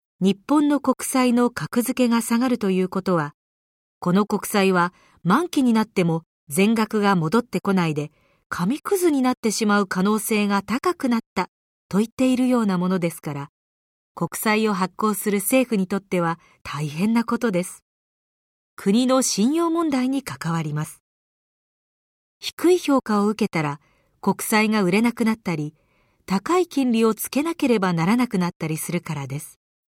[オーディオブック] 池上彰 経済ニュースのつぼ